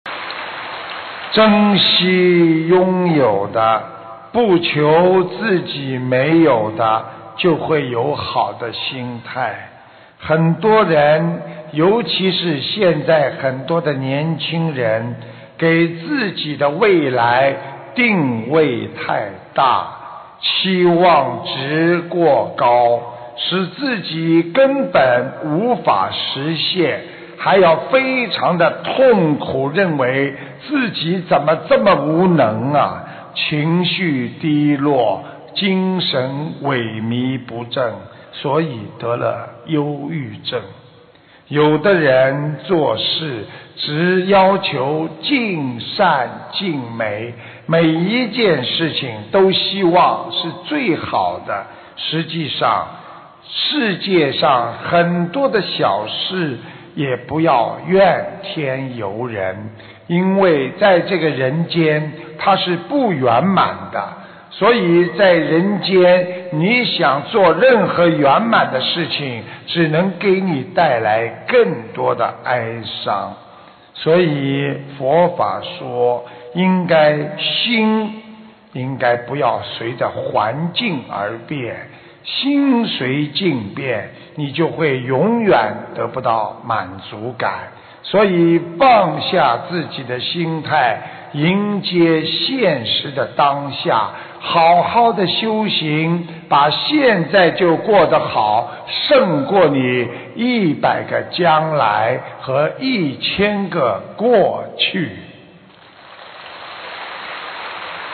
心灵净土»心灵净土 弘法视频 法会节选 视频：131.你想在人间做任何圆满的事情！只能给你带来更 ...
音频：你想在人间做任何圆满的事情！只能给你带来更多的哀伤！2016年8月13日！马来西亚法会槟城开示